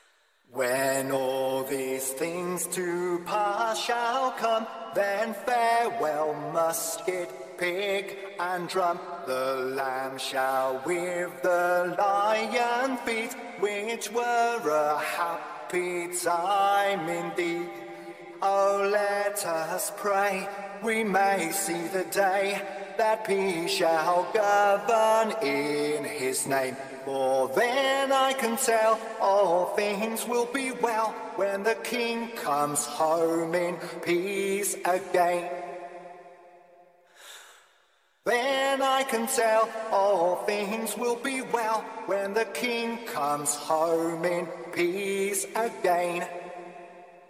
monarchy_anthem_REVISED.mp3